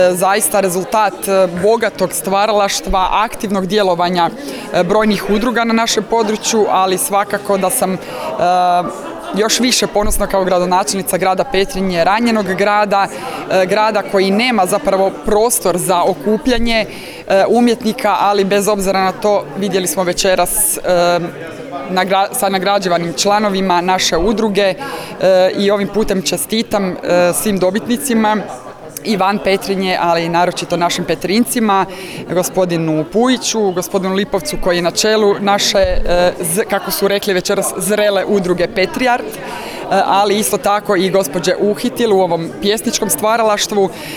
U organizaciji Zajednice kulturno – umjetničkih udruga SMŽ u prostoru Strukovne škole u Sisku uručena su priznanja i zahvalnice sudionicima natječaja za književna i likovna djela autora s područja naše županije za 2022. i 2023. godinu.
Saborska zastupnica i gradonačelnica Petrinje Magdalena Komes u ovoj je prigodi čestitala svim sudionicima i nagrađenima